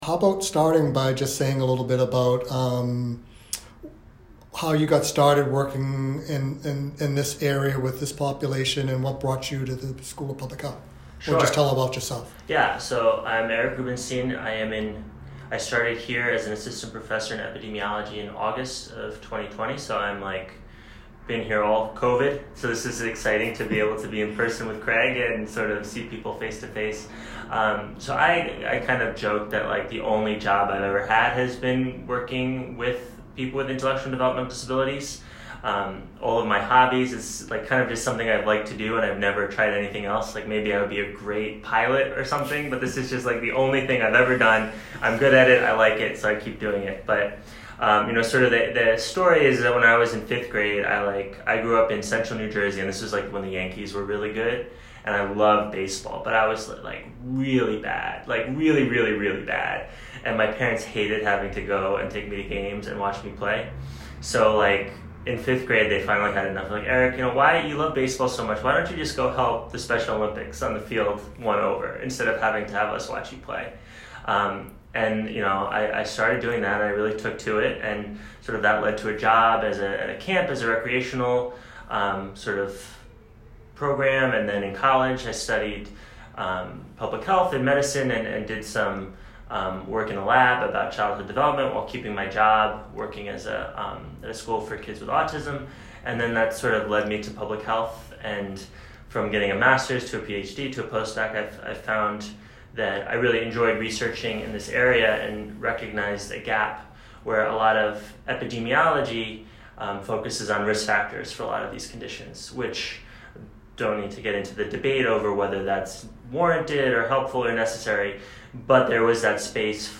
少见/少听：来自边缘的故事是活动家实验室的定期对话系列，专家们在其中讨论一系列紧迫的公共卫生主题。